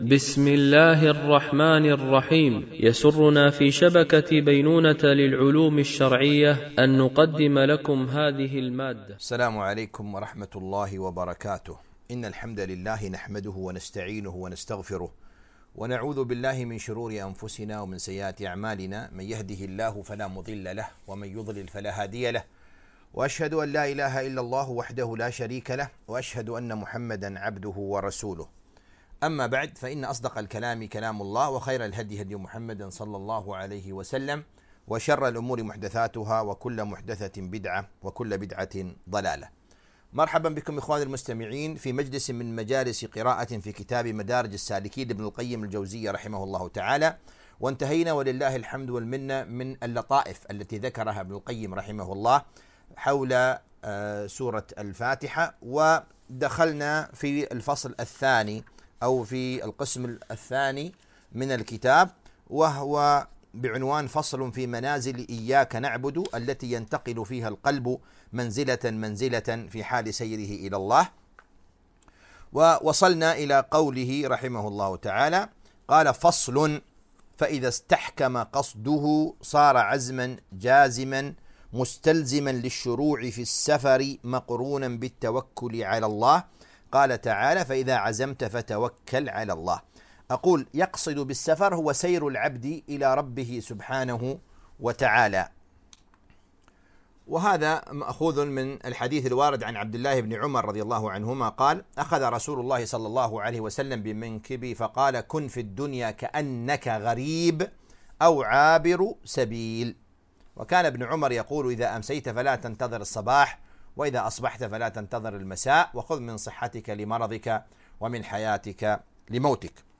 قراءة من كتاب مدارج السالكين - الدرس 19